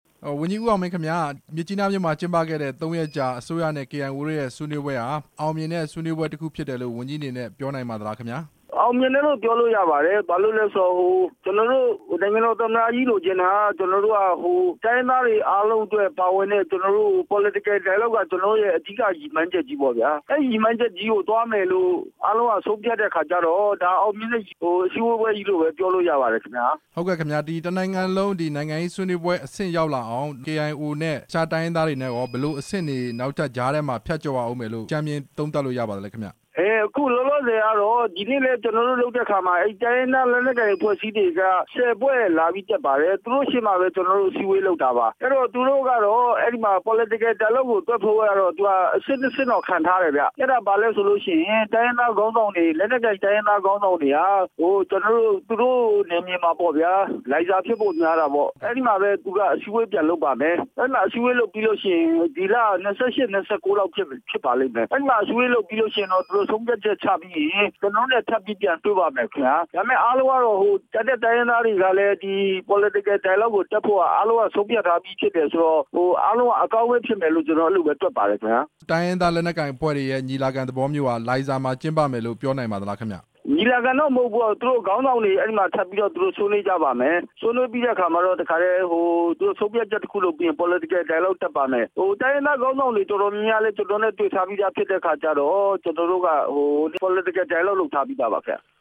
ပြည်ထောင်စုဝန်ကြီး ဦးအောင်မင်းနဲ့ မေးမြန်းချက်